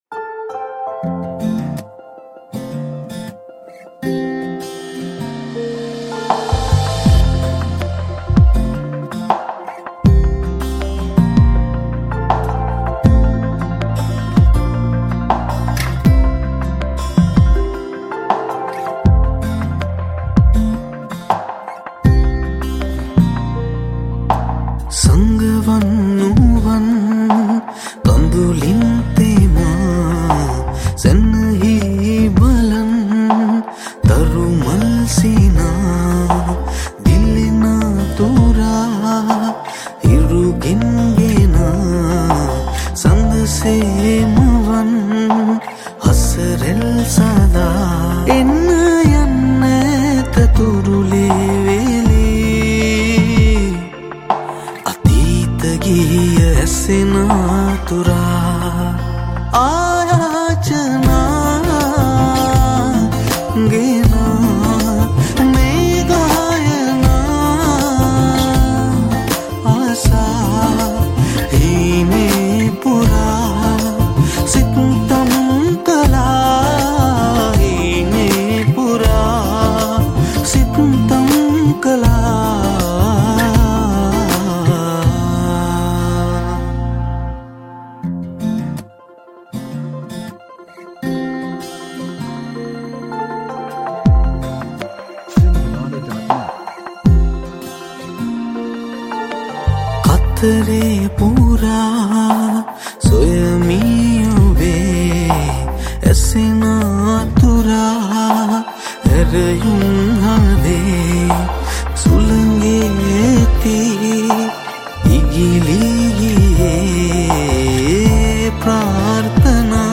Guitars, Tabla, Sitar, Recorder, Bass, Synths